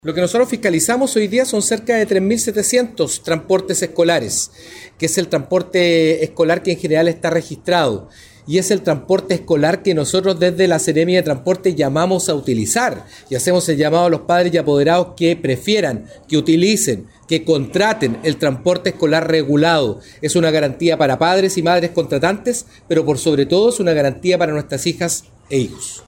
Cuna-3-Seremi-de-transporte-Patricio-Fierro-transporte-escolar.mp3